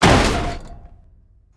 0335ec69c6 Divergent / mods / Soundscape Overhaul / gamedata / sounds / ambient / soundscape / underground / under_21.ogg 47 KiB (Stored with Git LFS) Raw History